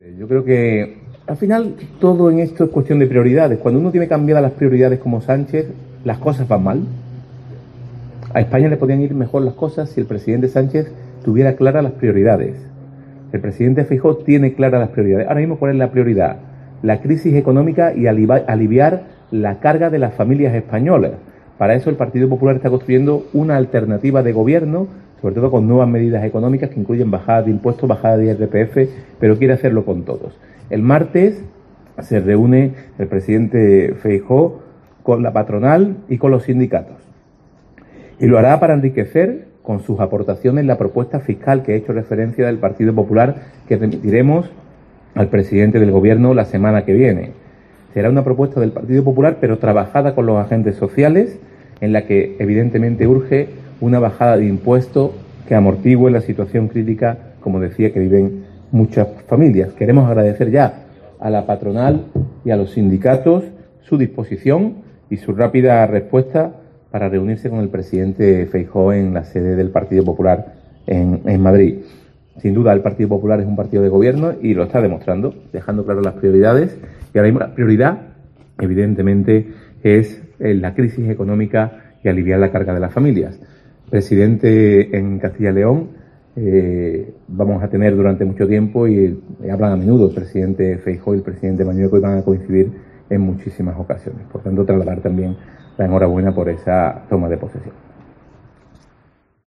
Declaraciones de Elias Bendodo sobre Nuñez Feijoo